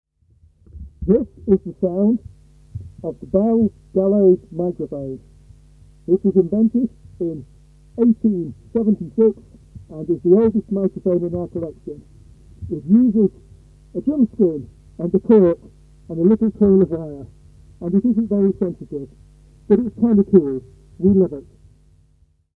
However, our own experiments show that with careful adjustment we can indeed record speech with this device, although it lacks sensitivity and the frequency response is peaky and very limited.
We connected the output from the transformer to a balanced microphone input on a UA Volt 2 interface, and spoke loudly into the diapghragm.
The sound is somewhat muffled and very peaky, but the voices are just about intelligible.
Bells_Gallows_Shouty_Voice.mp3